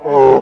Index of /server/sound/npc/poisonzombie
zombie_voice_idle4.wav